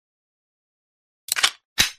Free Sci-Fi sound effect: Ion Blast.
Ion Blast
# ion # blast # weapon # scifi About this sound Ion Blast is a free sci-fi sound effect available for download in MP3 format.
423_ion_blast.mp3